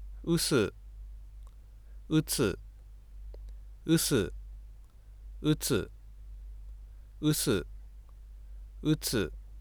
両者を比べると，右「ウツ」では最初の母音の直後に無音区間が見られることがわかると思います。